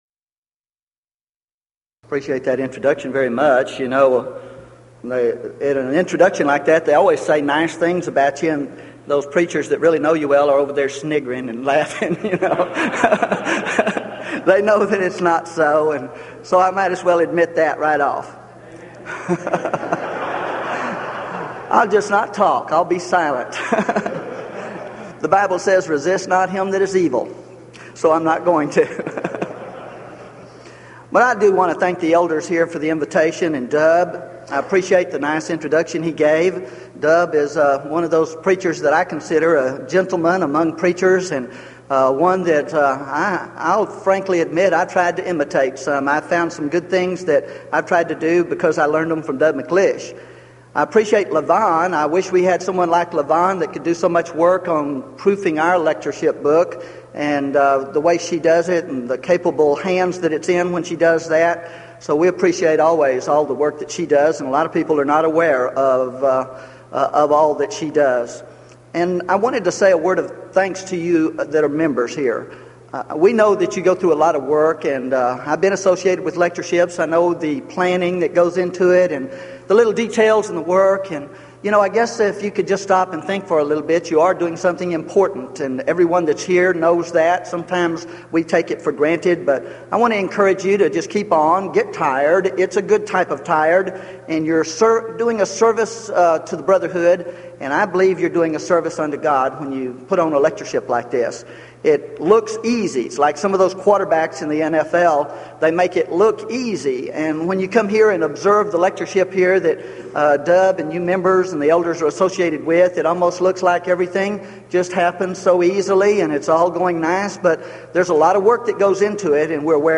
Title: DISCUSSION FORUM: Must We Respect The Silence Of Scripture As Well As Its Statement?
Event: 1993 Denton Lectures